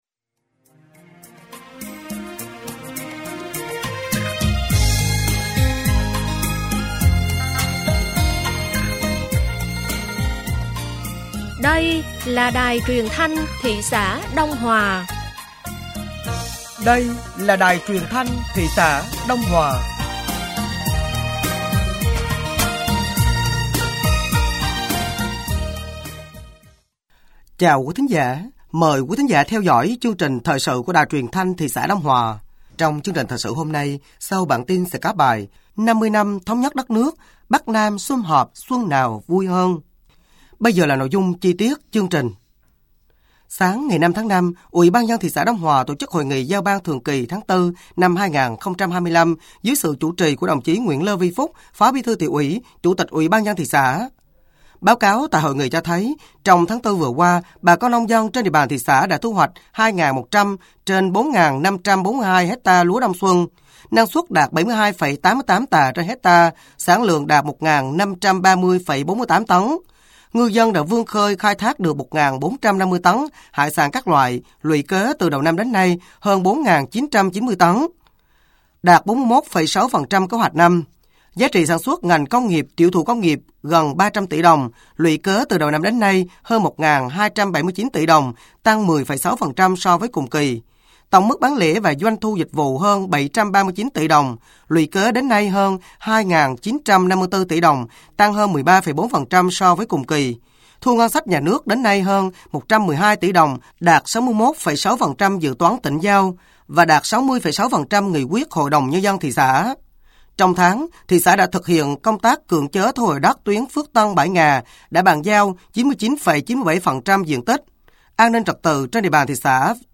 Thời sự tối ngày 05 và sáng ngày 06 tháng 5 năm 2025